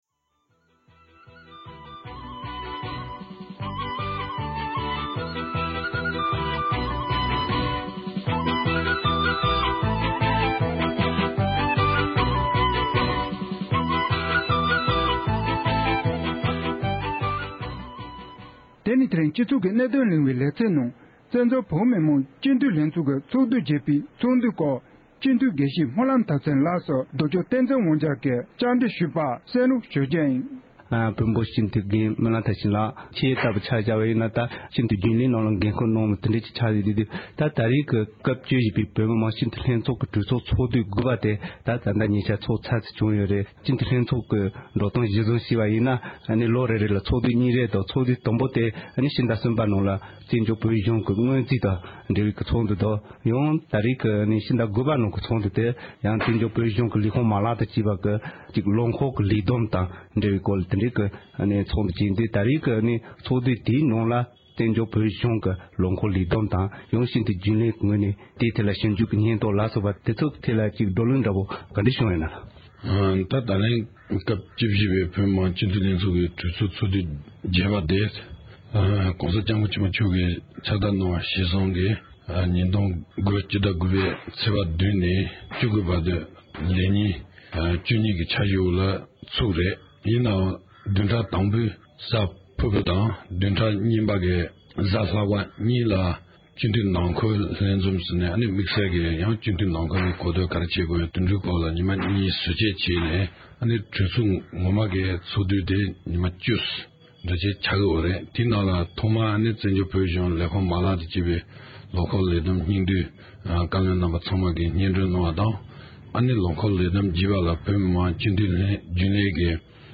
སྤྱི་འཐུས་དགེ་བཤེས་སྨོན་ལམ་ཐར་ཕྱིན་ལགས་ཀྱིས་བོད་མི་མང་སྤྱི་འཐུས་ལྷན་ཚོགས་ཀྱི་གྲོས་ཚོགས་ཚོགས་དུས་བརྒྱད་པའི་སྐོར་གསུངས་བ།